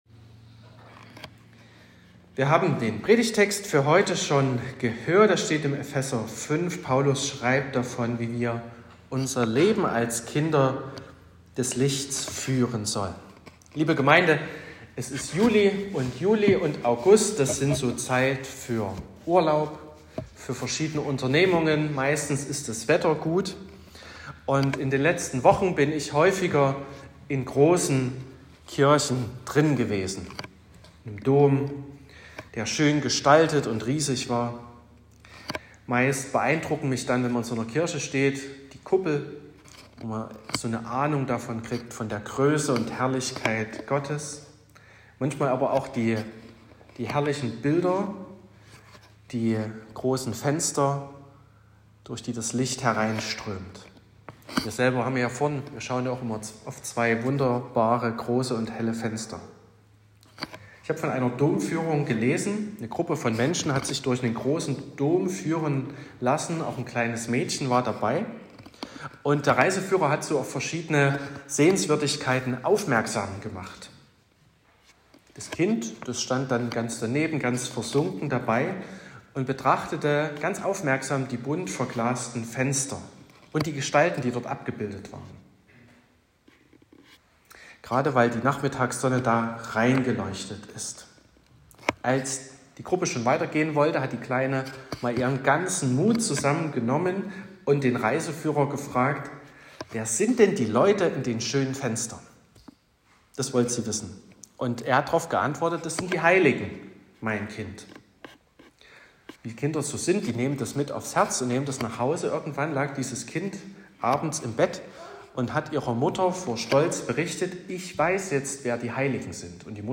21.07.2024 – Gottesdienst
Predigt und Aufzeichnungen